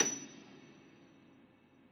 53g-pno28-F6.wav